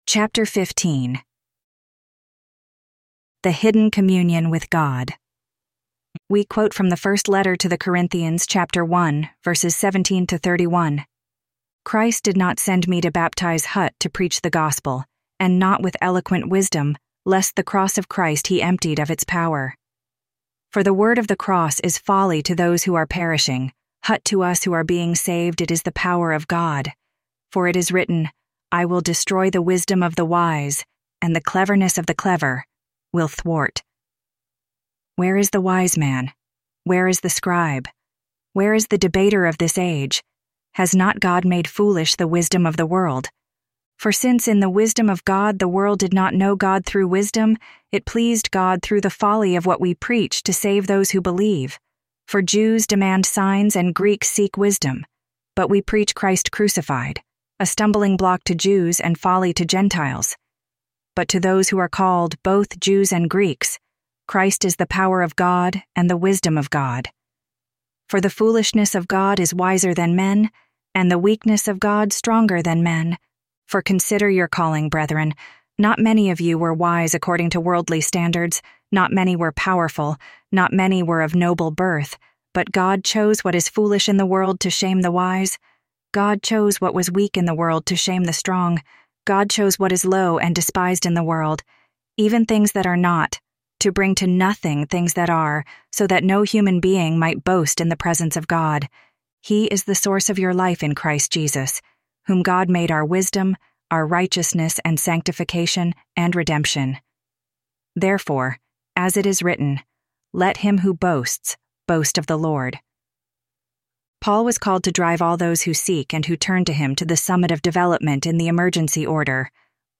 Audio Books of the Golden Rosycross